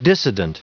Prononciation du mot dissident en anglais (fichier audio)
Prononciation du mot : dissident